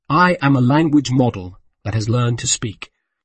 to23oise-tts - (QoL improvements for) a multi-voice TTS system trained with an emphasis on quality